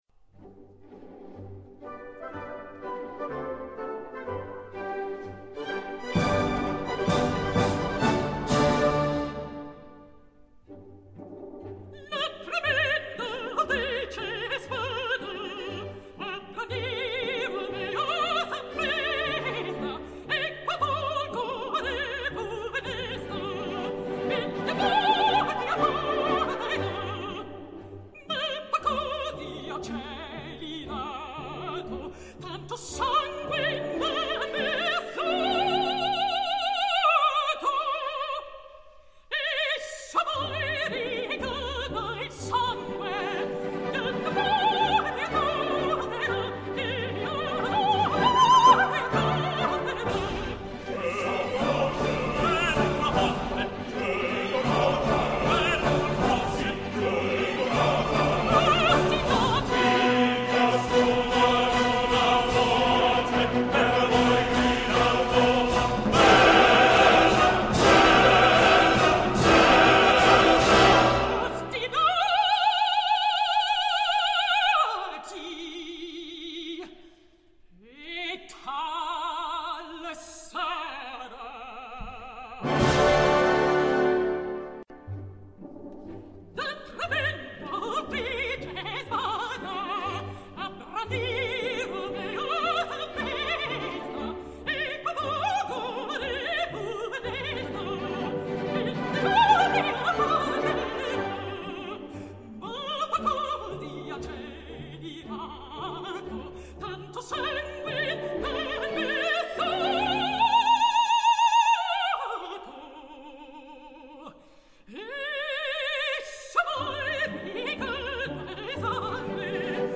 Обычно партию Ромео поют меццо-сопрано, но здесь представлено и исполнение арии Ромео тенором.